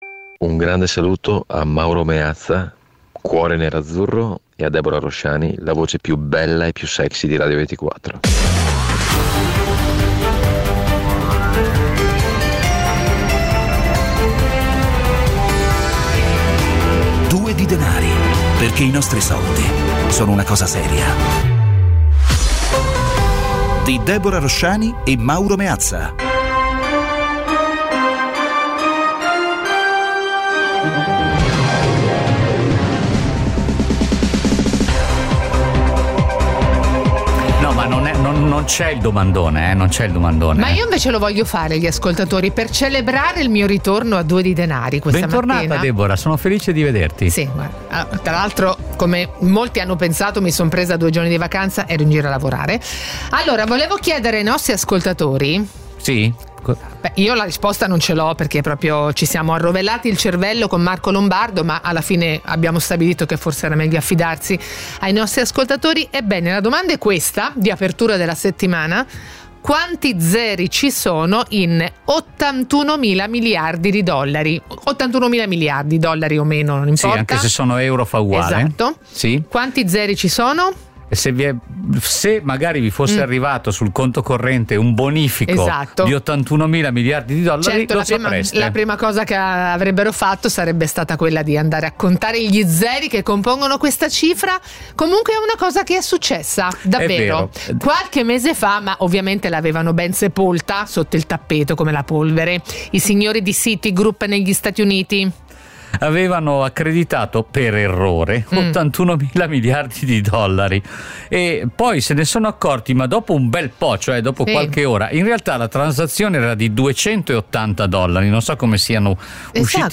Ogni giorno, su Radio 24, in questo spazio vengono affrontati con l'aiuto degli ospiti più competenti, uno sguardo costante all'attualità e i microfoni aperti agli ascoltatori.
La cifra, da sempre, è quella dell’ "autorevoleggerezza" : un linguaggio chiaro e diretto, alla portata di tutti.